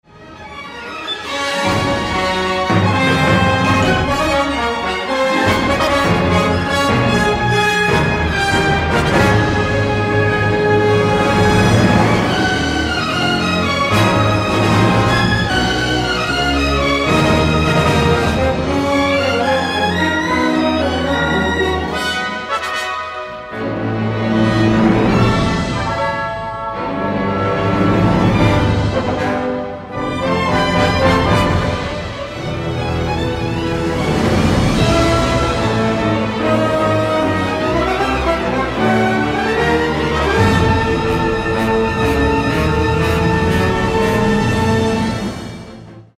LugarClub Campestre